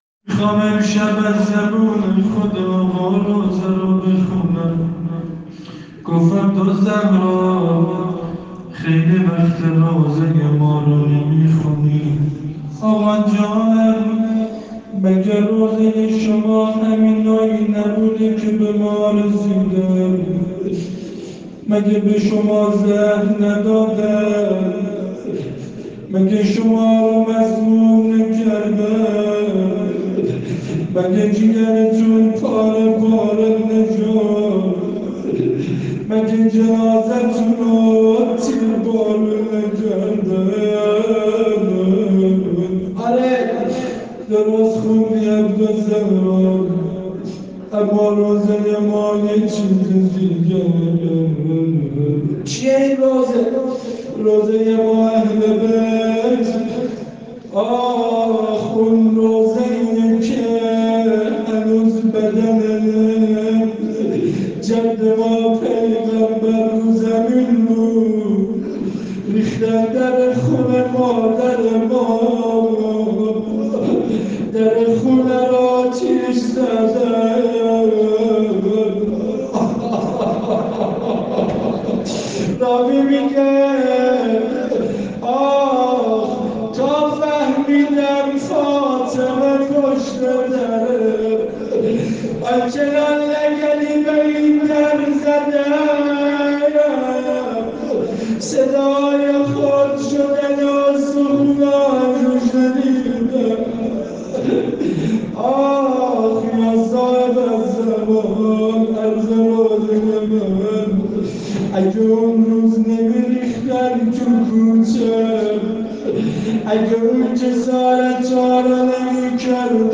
روضه ی فاطمه الزهرا(س)